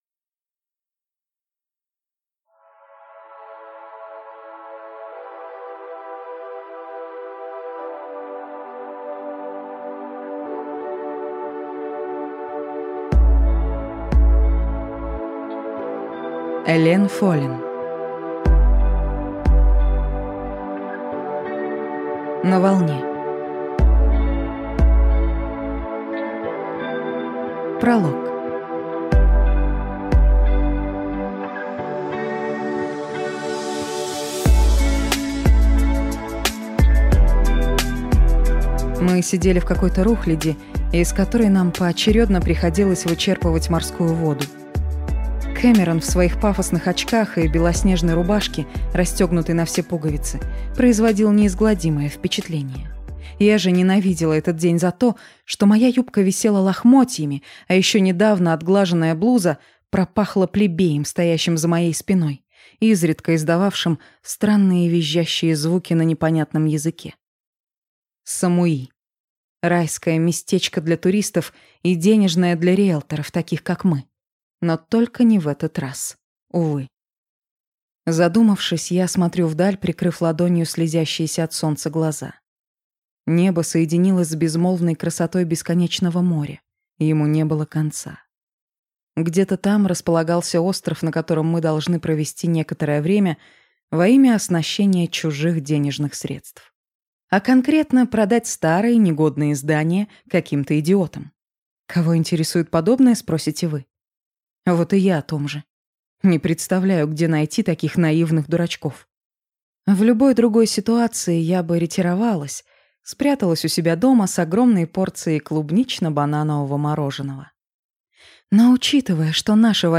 Аудиокнига На волне | Библиотека аудиокниг